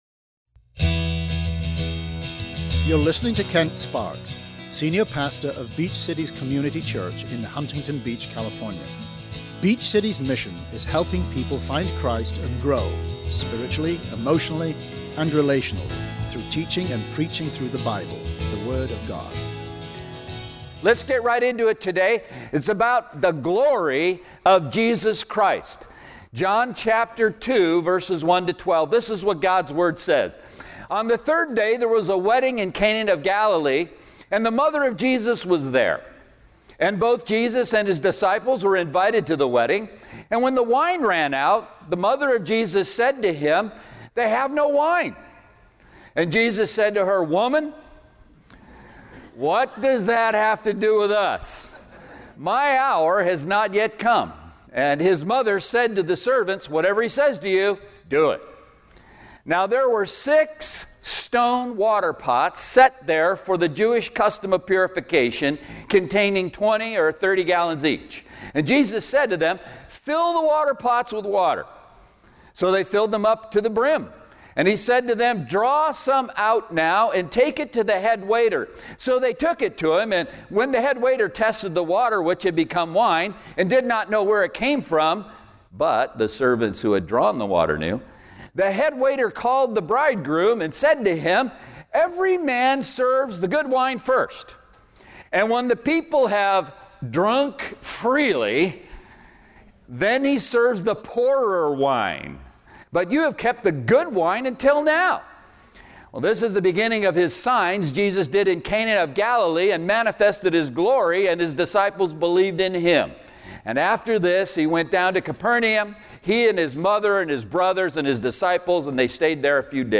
Then we see five truths about miracles and five points about how we see the glory of Jesus. SERMON AUDIO: SERMON NOTES: